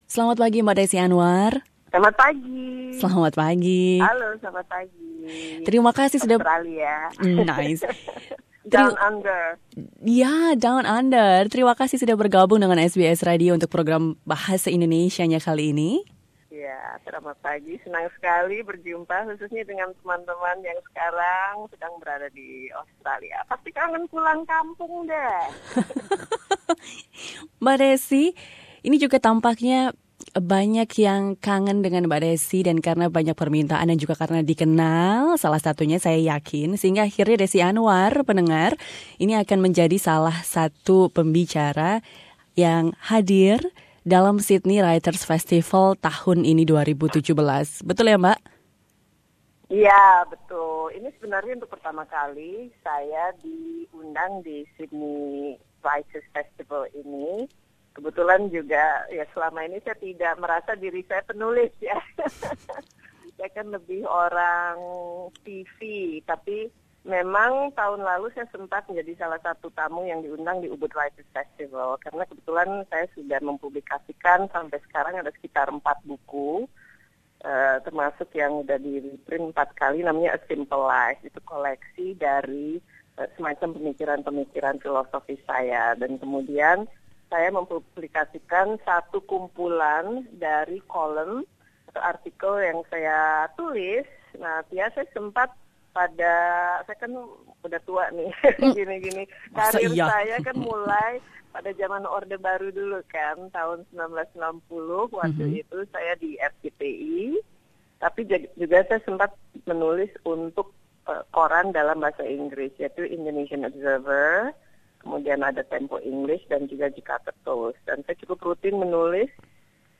Desi Anwar - kolumnis, pembawa berita dan jurnalis senior Indonesia - berbicara tentang tulisan dan keikutsertaannya sebagai salah satu pembicara dalam Sydney Writers' Festival 2017.